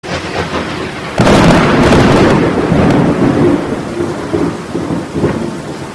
Klingelton Sound Of Raindrops
Kategorien Soundeffekte